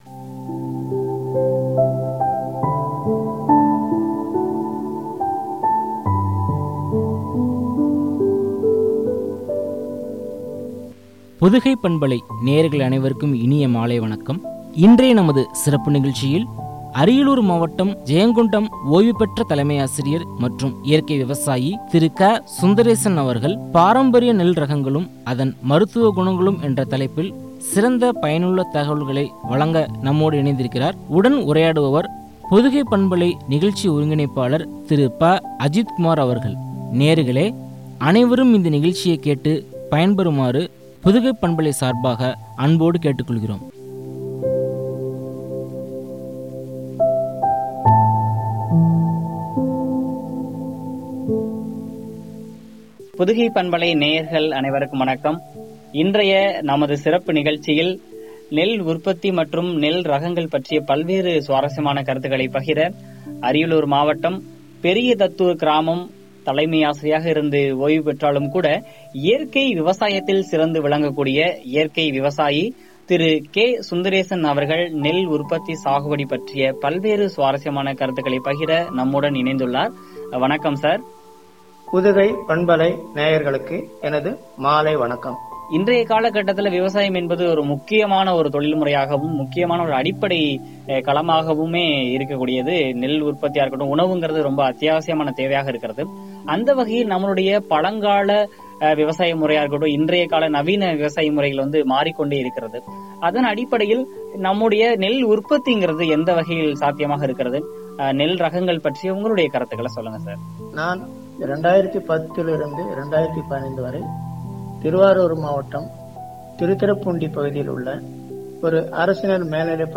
பாரம்பரிய நெல் ரகங்களும் , மருத்துவ குணங்களும் குறித்து வழங்கிய உரையாடல்.